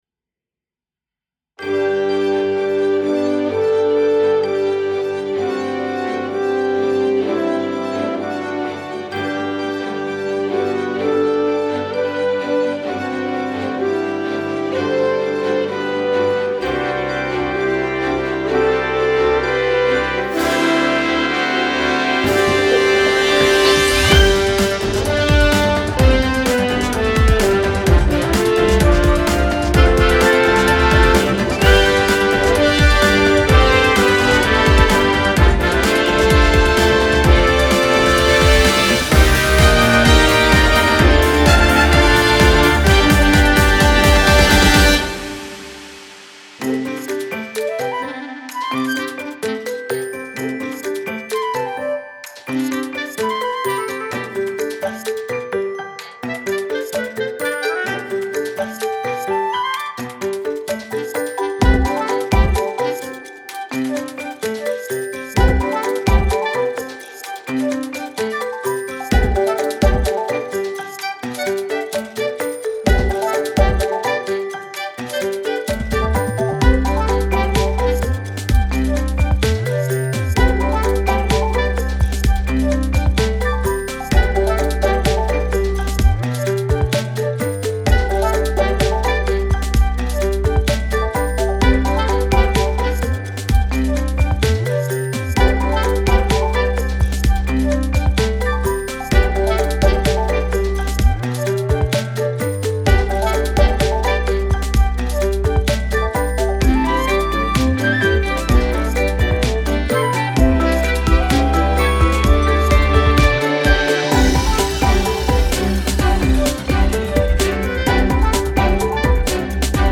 INSTRUMENTAL ELECTRO